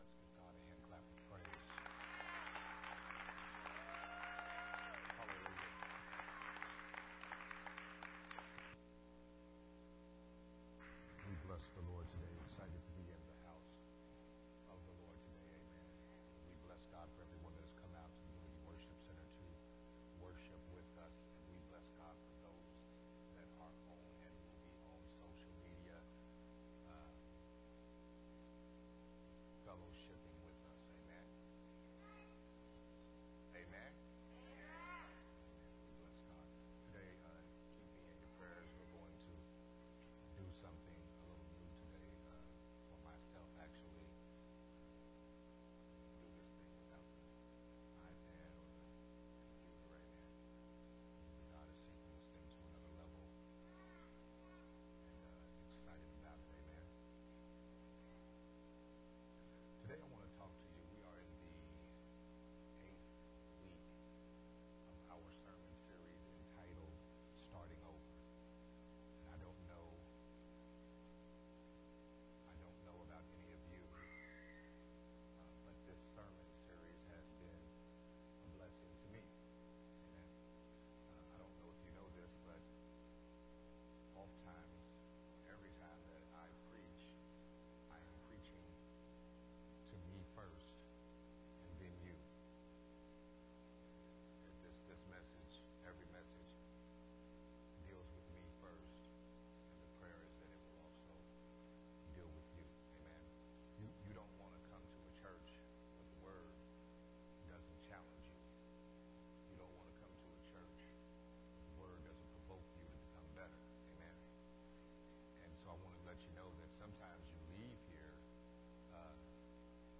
Part 8 of the sermon series
recorded at Unity Worship Center